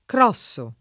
crossare v. (sport.); crosso [ kr 0SS o ]